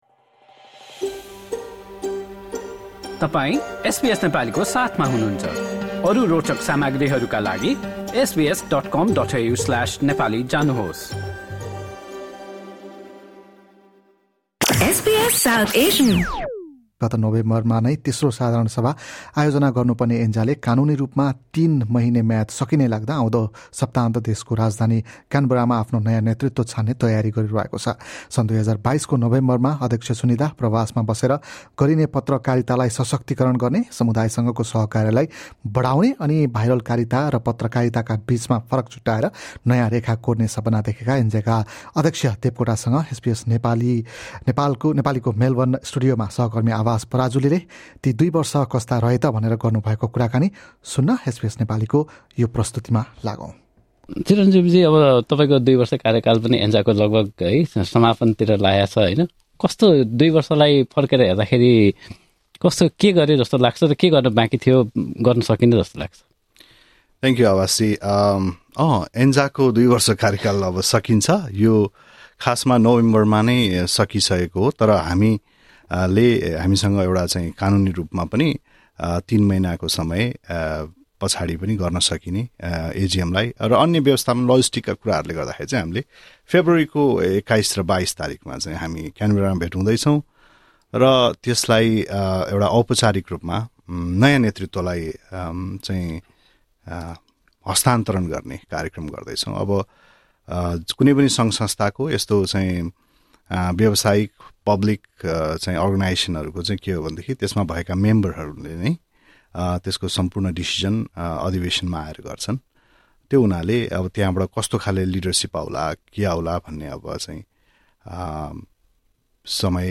at SBS studios Melbourne